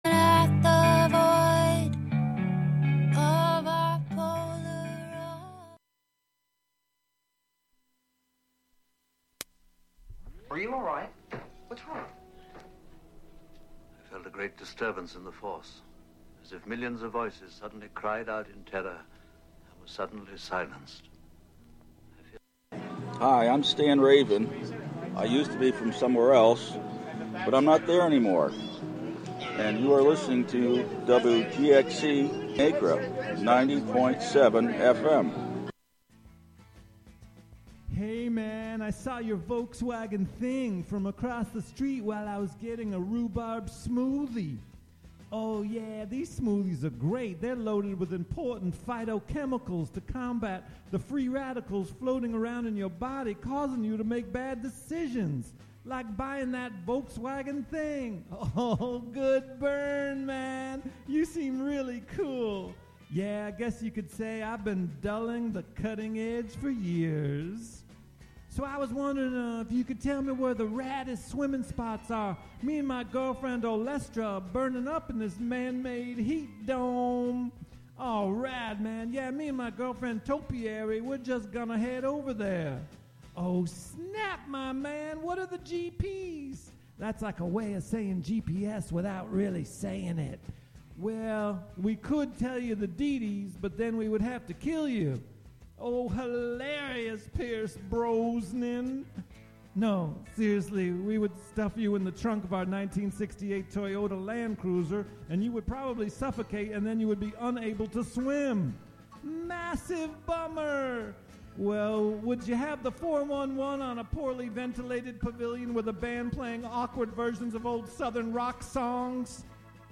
polyrhythmic music